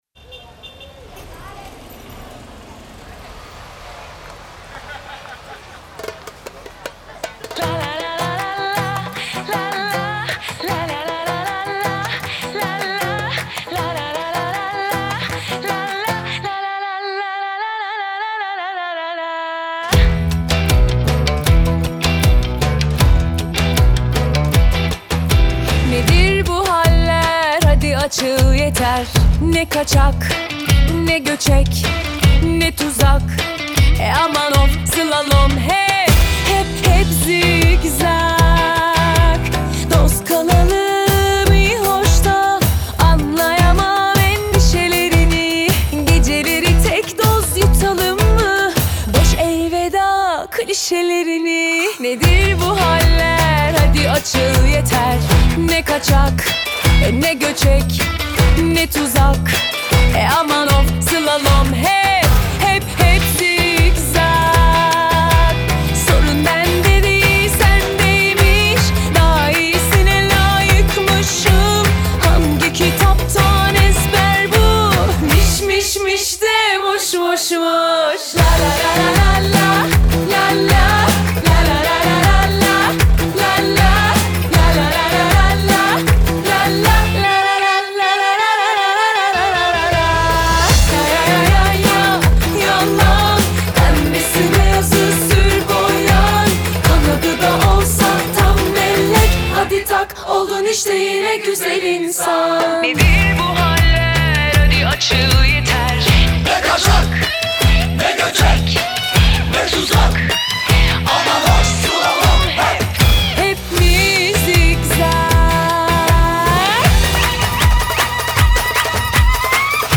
• Жанр: Турецкие песни